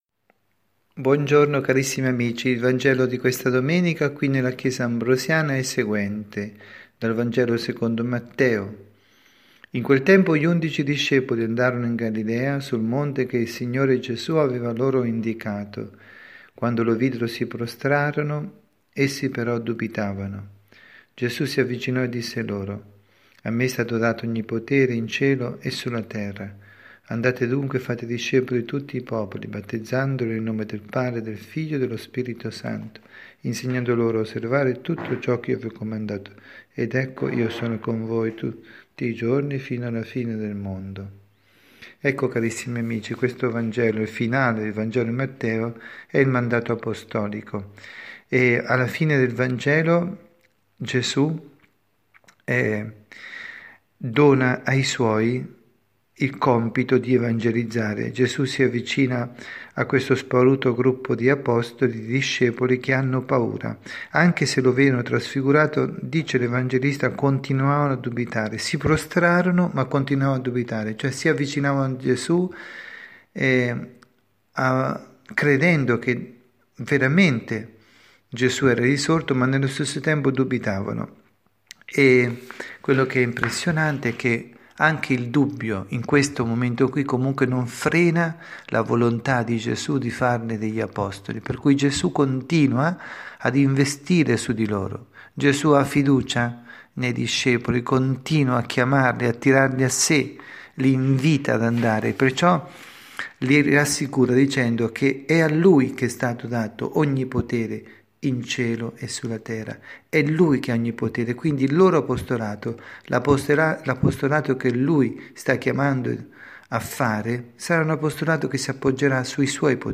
avvisi, Omelie
Catechesi